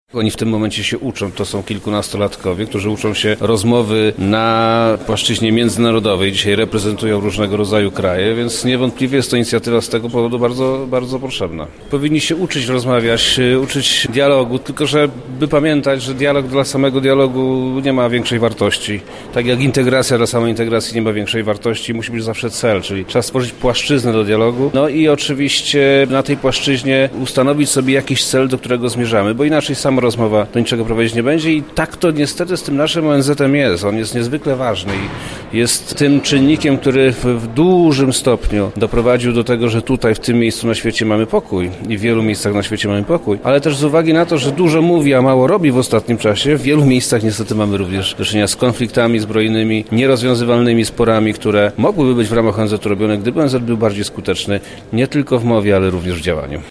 O inicjatywie mówi Wojewoda Lubelski Przemysław Czarnek